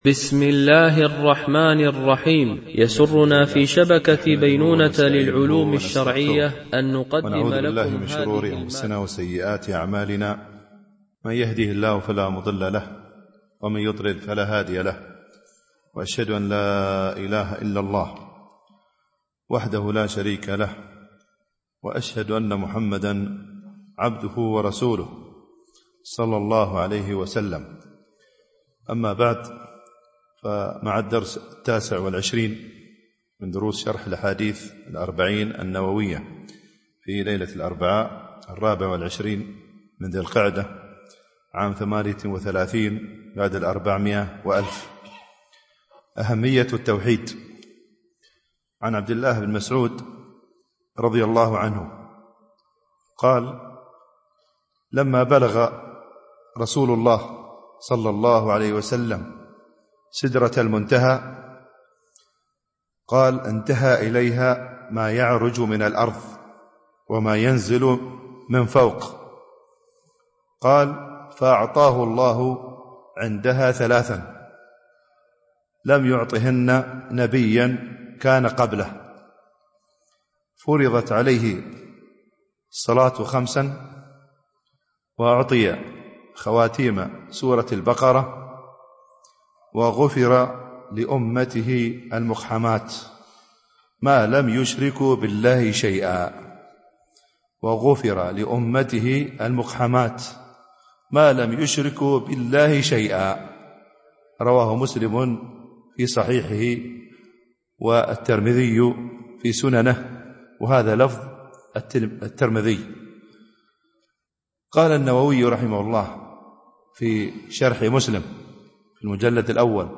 شرح الأربعين النووية ـ الدرس 29 (الحديث 16)